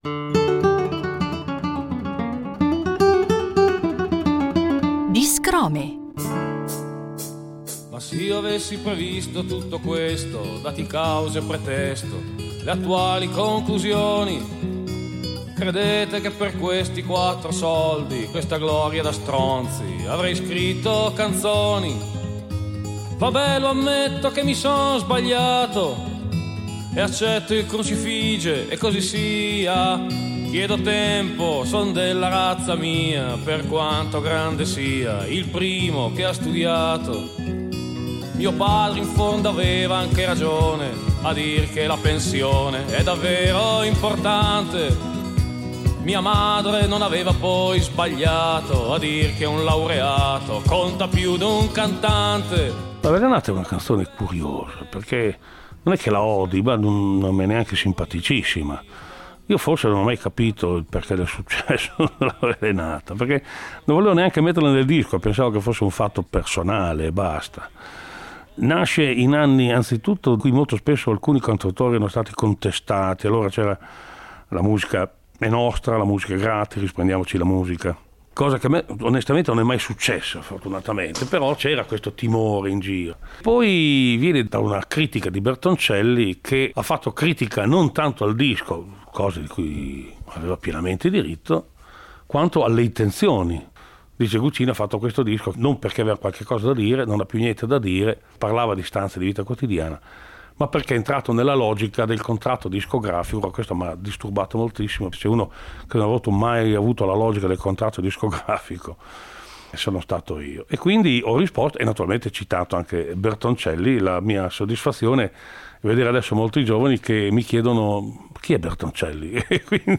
Una preziosa serie di chicche tratte dai nostri archivi: il grande cantautore emiliano Francesco Guccini introduce a modo suo alcune delle sue canzoni più note e amate dal pubblico.